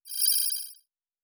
pgs/Assets/Audio/Sci-Fi Sounds/Interface/Data 09.wav at 7452e70b8c5ad2f7daae623e1a952eb18c9caab4